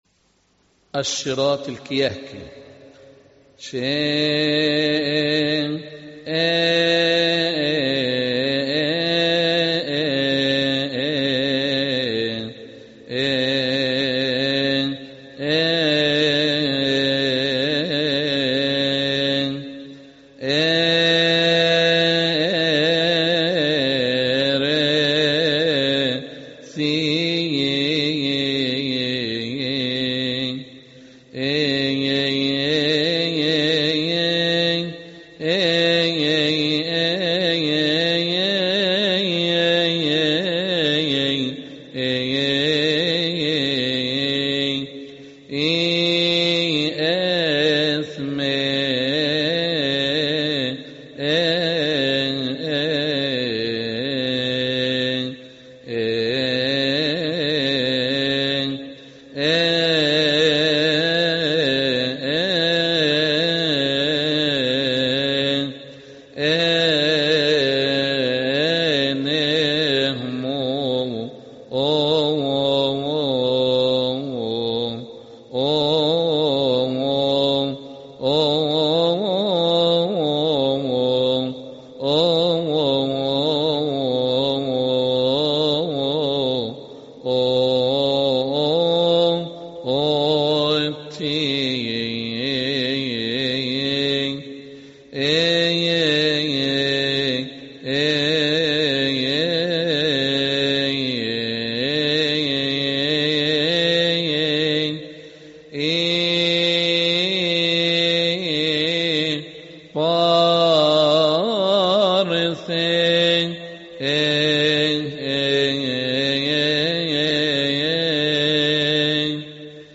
لحن شيريه ثى إثميه إن إهموت
المصدر : المرتل أبراهيم عياد يحتوي هذا التسجيل علي: لبش ثيؤطوكية السبت (الشيرات الكيهكي) يصلي في تسبحة عشية أحاد شهر كيهك للمرتل أبراهيم عياد، قبطي.